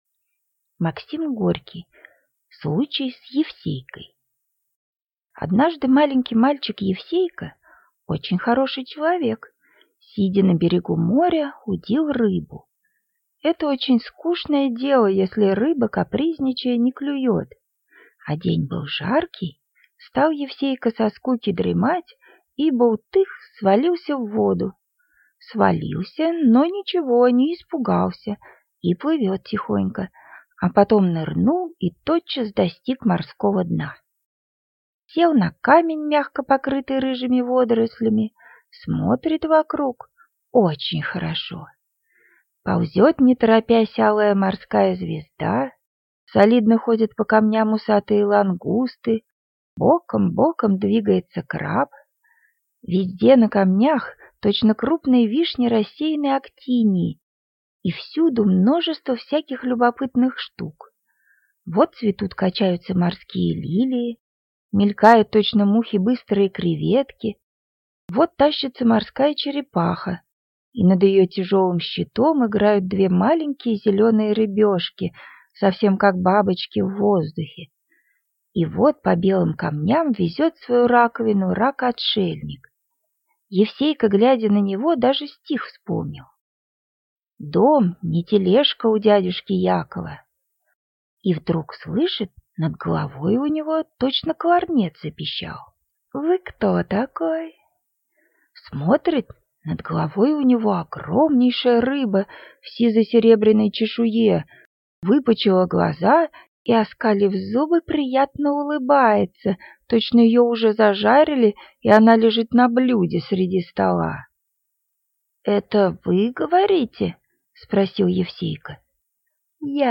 Аудиокнига Случай с Евсейкой | Библиотека аудиокниг